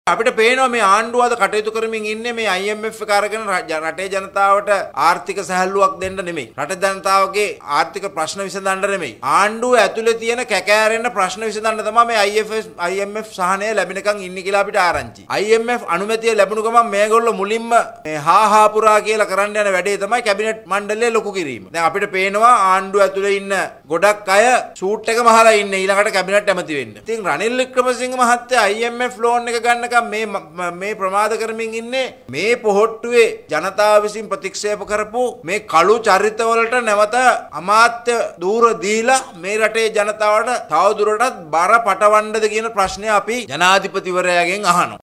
මේ අතර අද පැවති මාධ්‍ය හමුවකදී සමගි ජනබලවේගයේ පාර්ලිමේන්තු මන්ත්‍රී නලින් බණ්ඩාර මහතා චෝදනා කළේ ආණ්ඩුවේ ප්‍රශ්න විසදා ගැනීම සදහා මූල්‍ය අරමුදලේ සහන ලබා ගන්නා බවටයි.